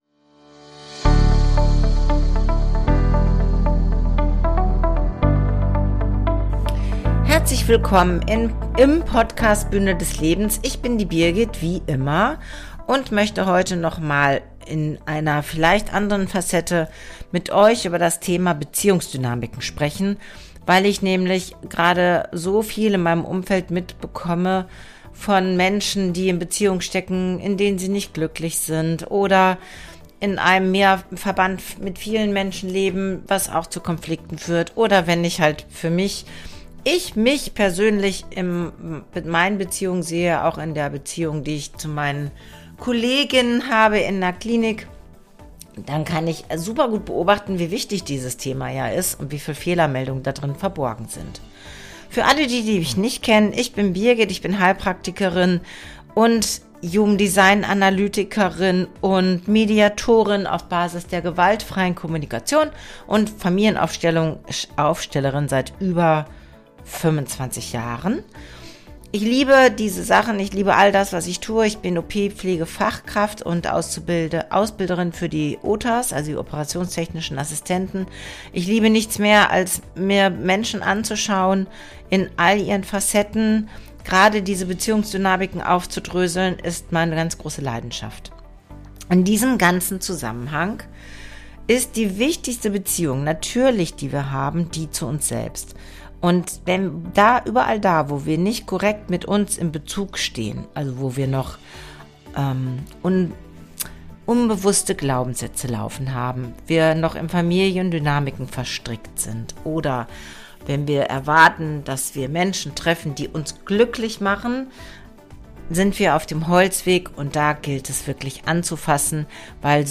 Am Ende erwartet dich ein bewegender Song, der dich daran erinnert, dass du nicht falsch bist.